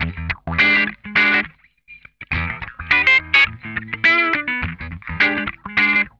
CRUNCHWAH 1.wav